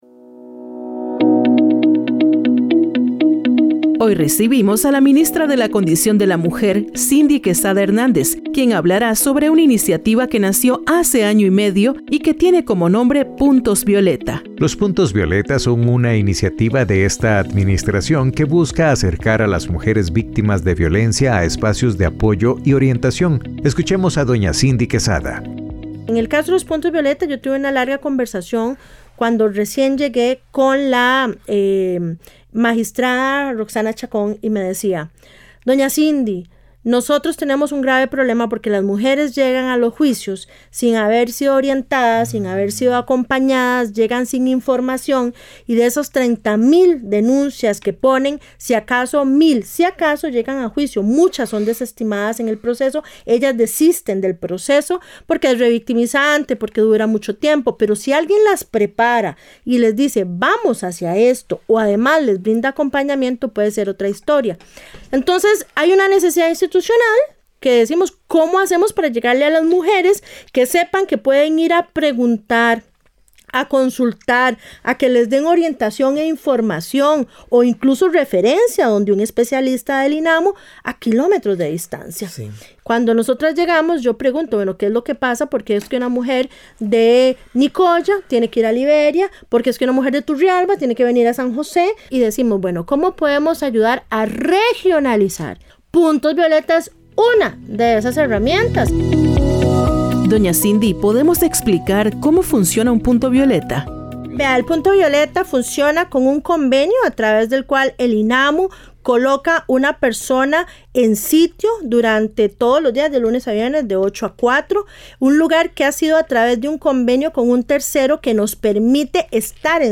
Hoy recibimos a la ministra de la Condición de la Mujer, Cindy Quesada Hernández, sobre una iniciativa que nació hace año y medio y que tiene como nombre Puntos Violeta.